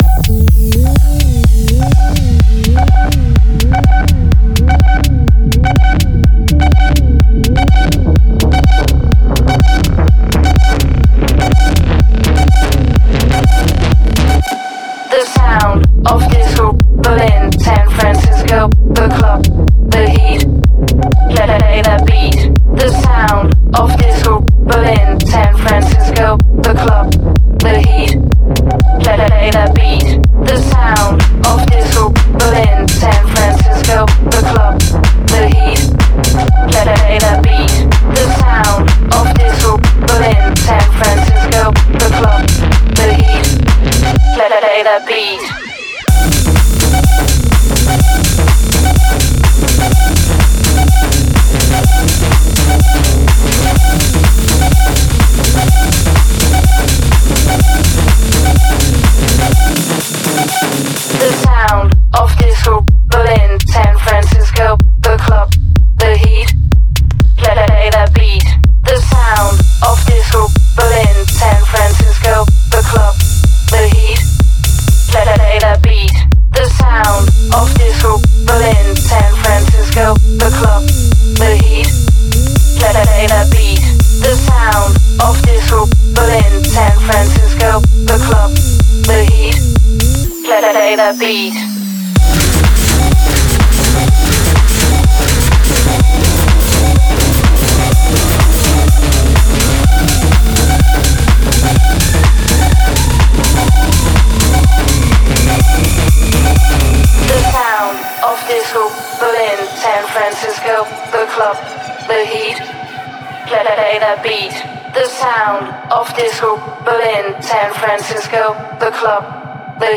это энергичная трек в жанре электро-хаус